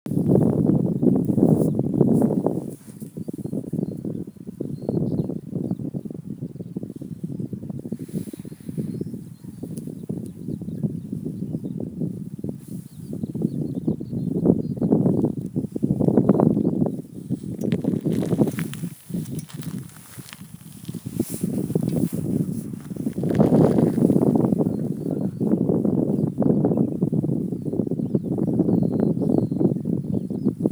Birds -> Larks ->
Skylark, Alauda arvensis
StatusSpecies observed in breeding season in possible nesting habitat